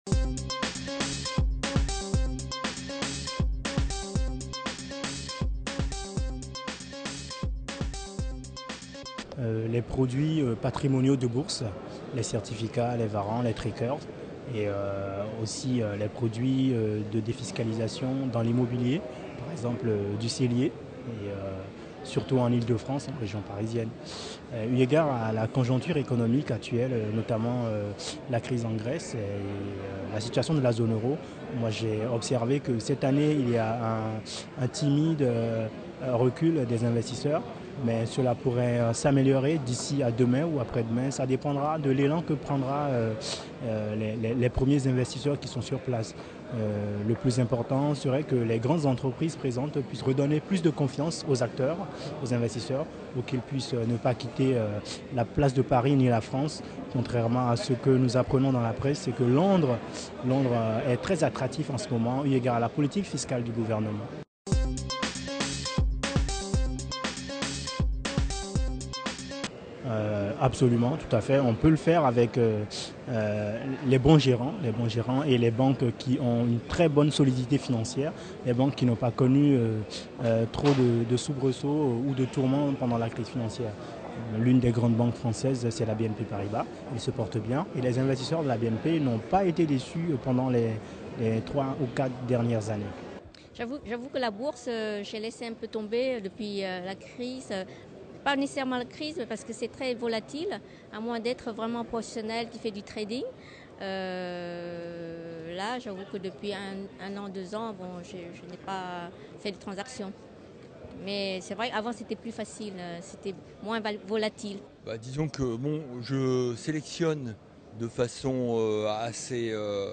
Forum de l'Investissement 2012 : Les épargnants témoignent .
Sur le Forum de l’Investissement, avis de particuliers qui cherchent des conseils sur les stands proposés : Bourse, immobilier, or, ils veulent trouver des solutions.